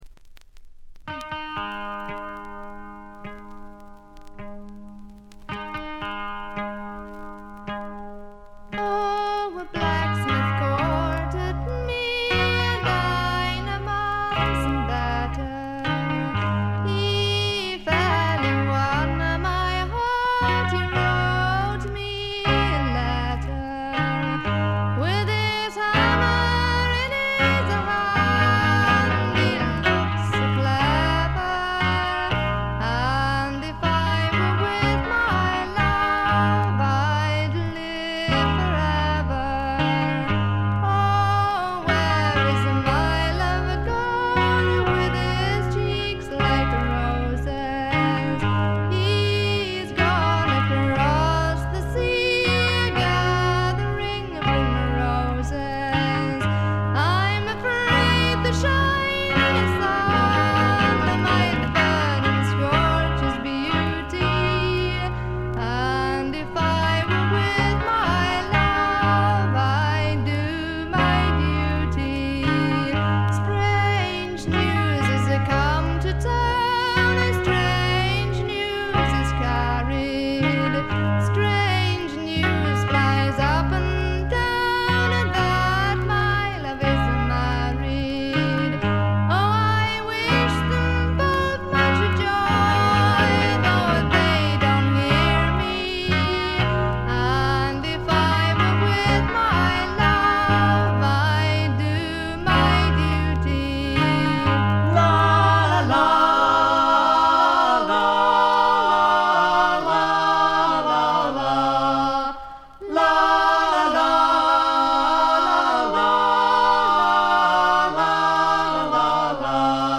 これ以外は静音部で軽微なチリプチ、バックグラウンドノイズ。
英国エレクトリック・フォーク最高峰の一枚。
試聴曲は現品からの取り込み音源です。